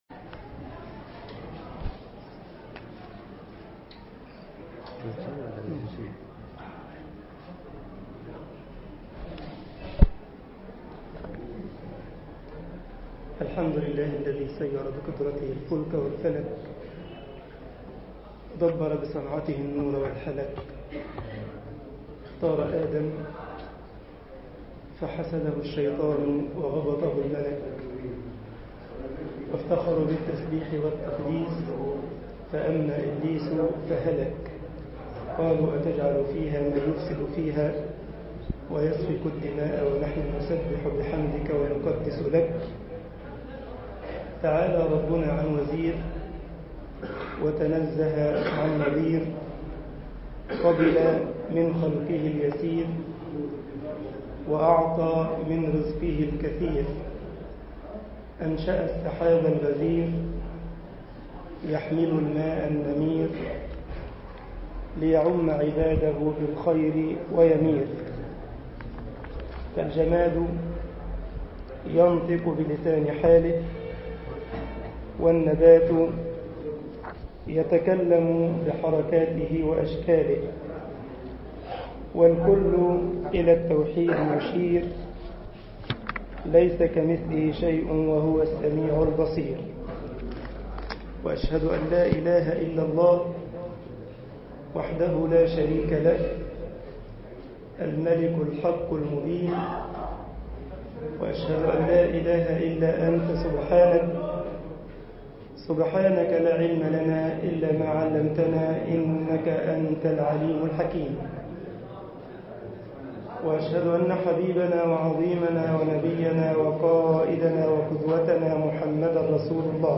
مسجد غمرة المنوفي ـ الشرابية ـ القاهرة